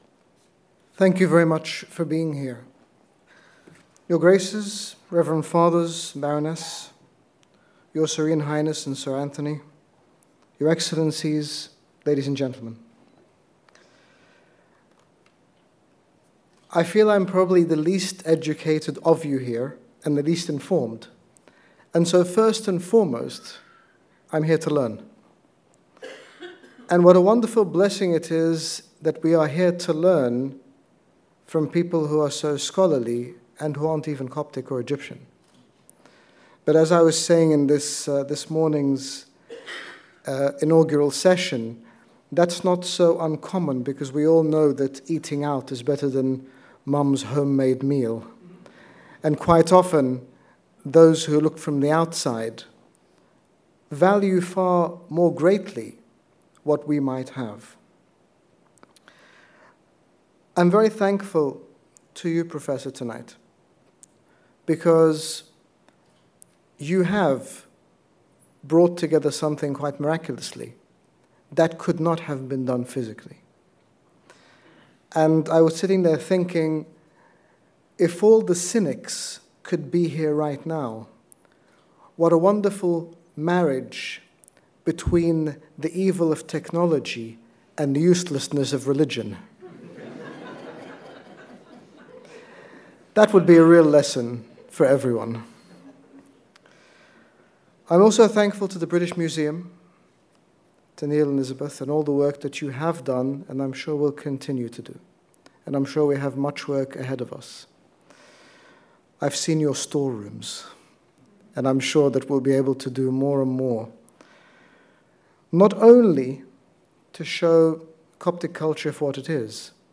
Address at the British Museum - HG Bishop Angaelos
His Grace Bishop Angaelos, General Bishop of the Coptic Orthodox Church in the United Kingdom gave this concluding speech at the British Museum on 5 June 2015 regarding the third international Coptic Symposium hosted at The Coptic Centre in Stevenage, United Kingdom 5-7 June 2015.
HG Sym Speech British Museum.mp3